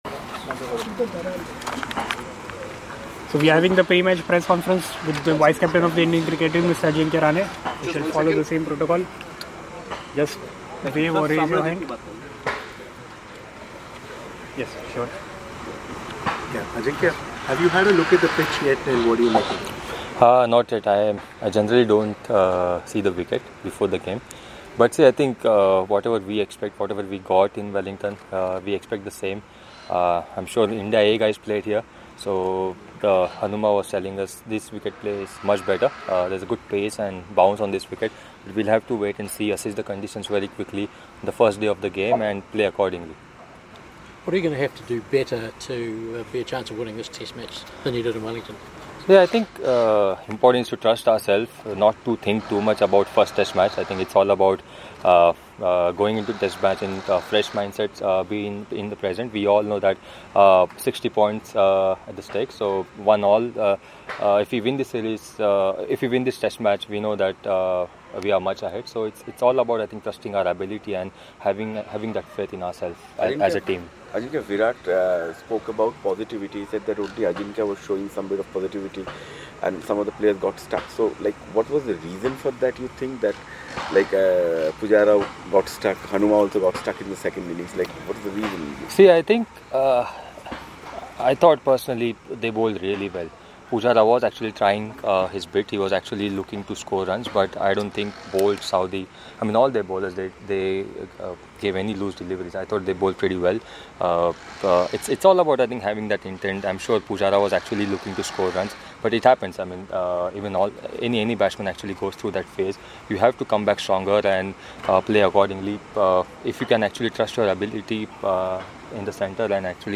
Ajinkya Rahane spoke to the media in Christchurch ahead of the 2nd Test against New Zealand.